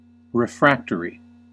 Ääntäminen
IPA : /ɹɪˈfɹæk.təɹ.i/